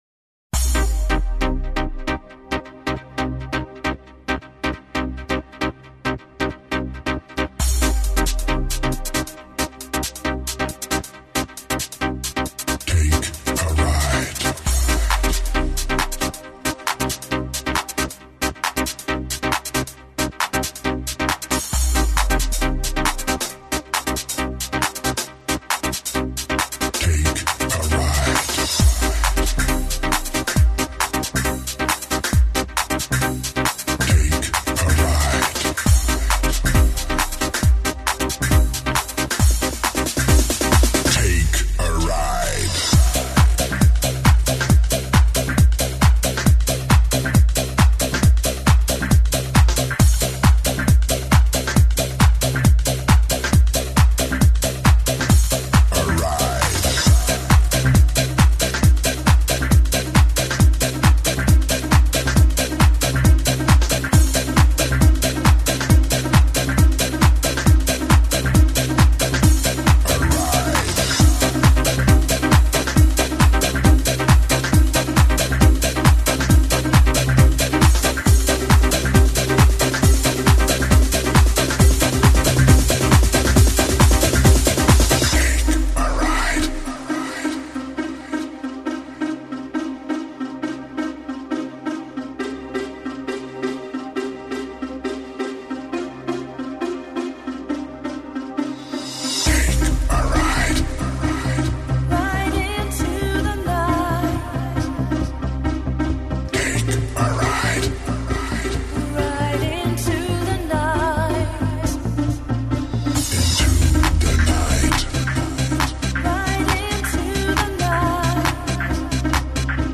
Жанр: Progressive House